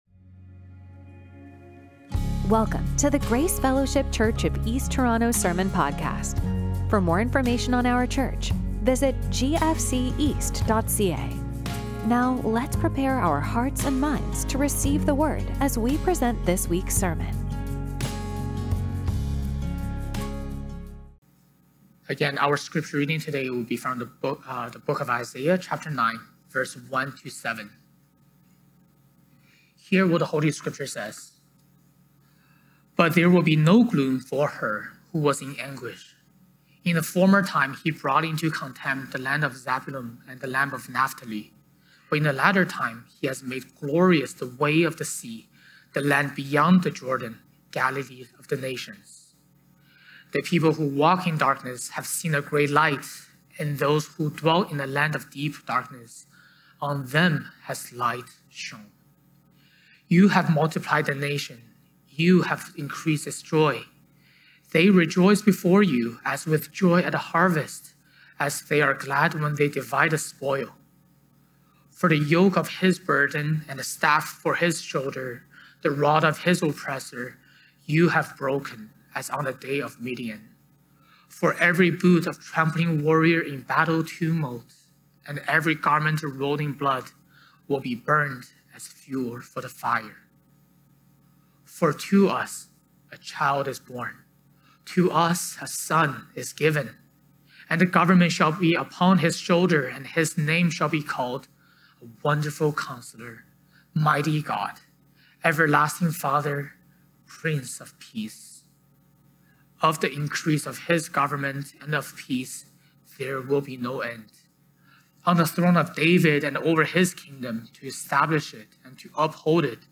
Sermons from Grace Fellowship Church East Toronto